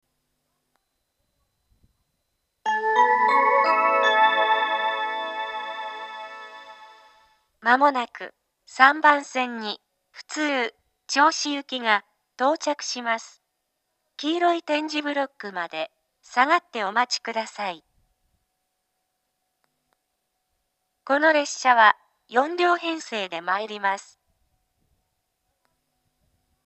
２０１９年１２月２４日には放送装置が更新され、自動放送が合成音声に変更されています。
同時に接近チャイムと発車メロディーの音質が向上し、接近放送の言い回しが「黄色い点字ブロック」に変更されました。
３番線接近放送
yachimata3bansen-sekkin2.mp3